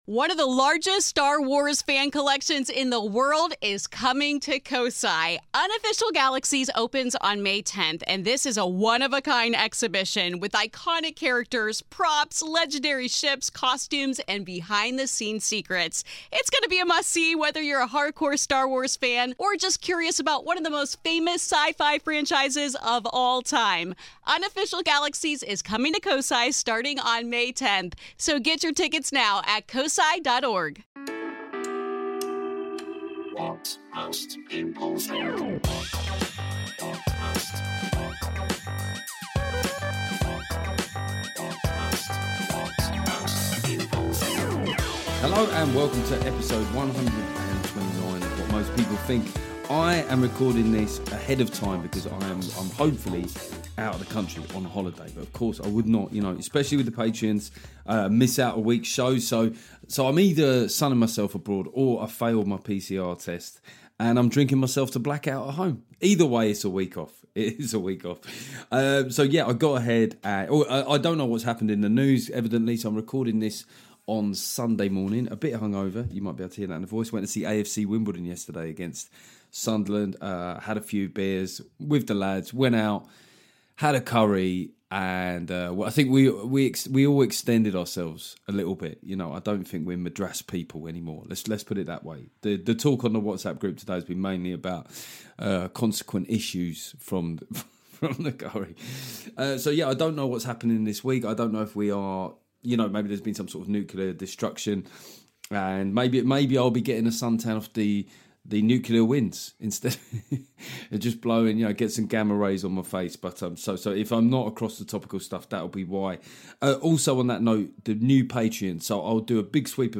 Despite being officially on holiday, I got ahead and scheduled this great chat with Russell Kane. Russell is a brilliant live stand-up but here we get a sense of his all around savvy in and around issues of class and censorship.